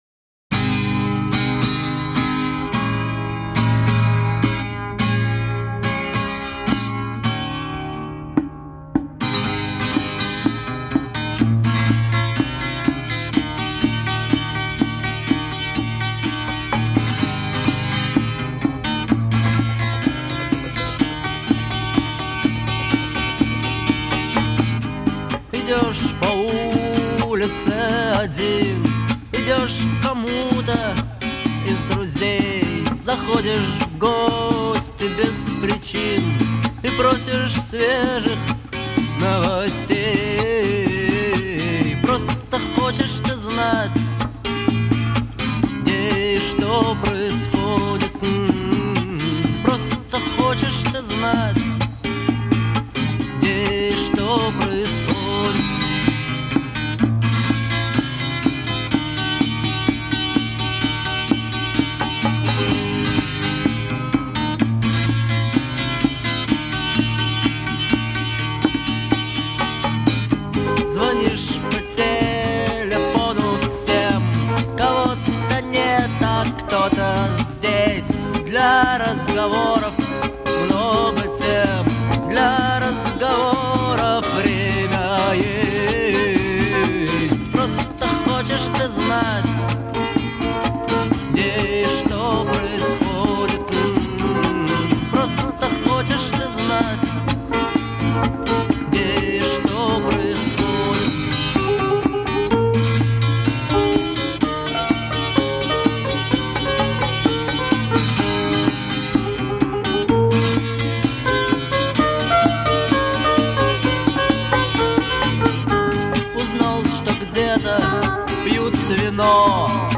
характерные гитарные рифы
эмоциональный вокал